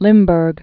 (lĭmbûrg, -bœr)